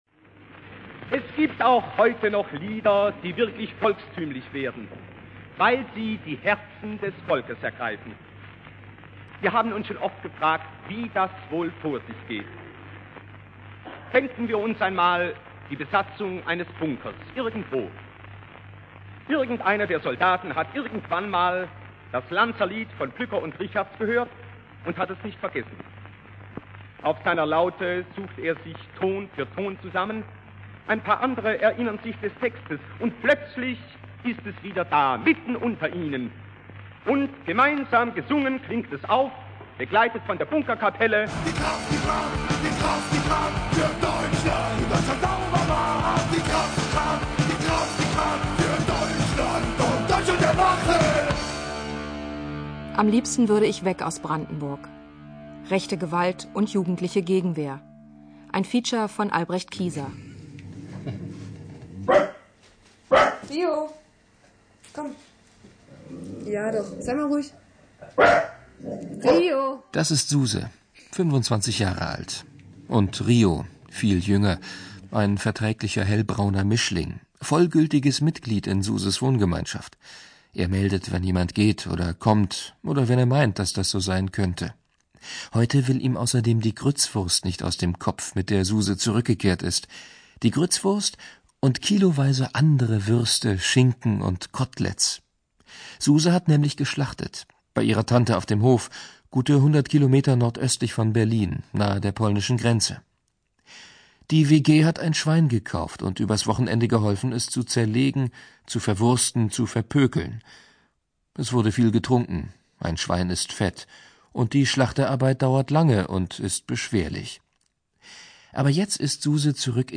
Deutschlandfunk, Politisches Feature, 5.3.2002
Es gibt einen Mitschnitt der Sendung.